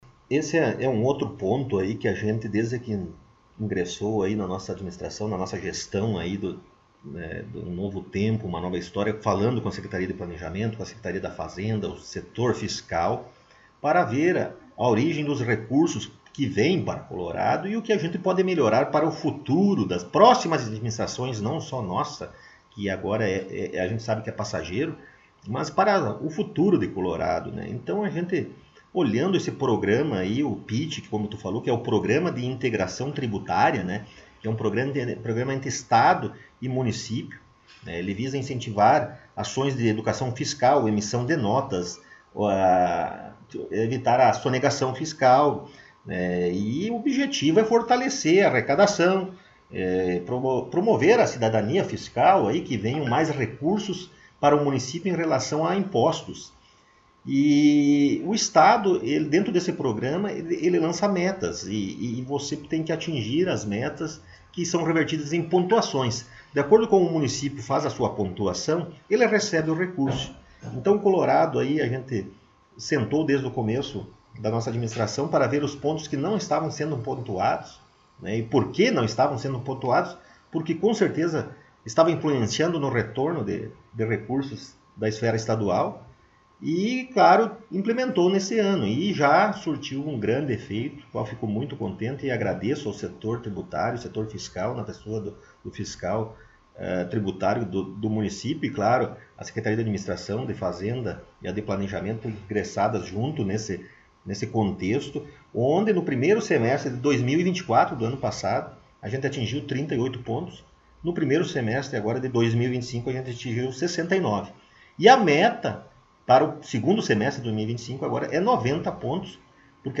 Rodrigo Sartori: Prefeito Municipal concedeu entrevista